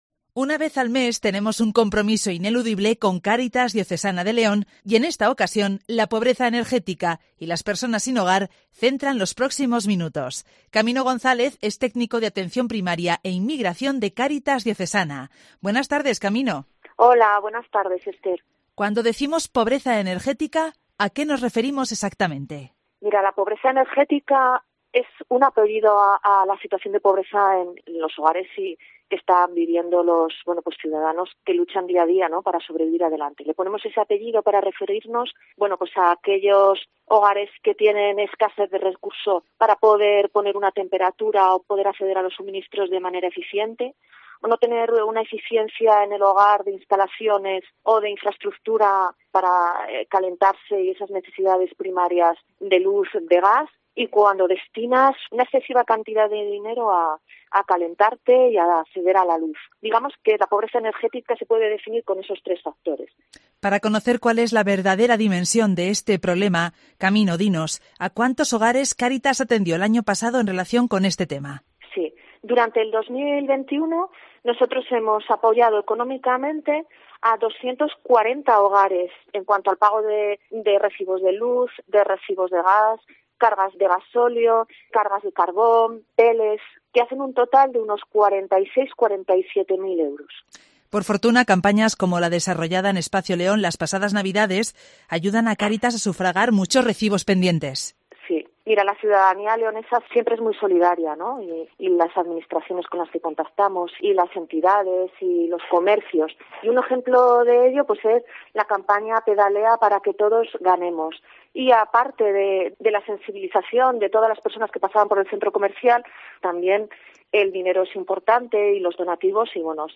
participa hoy martes 25 de enero en el programa local de Cope León para analizar la situación de las personas que sufren pobreza energética y aquellas que no tienen hogar.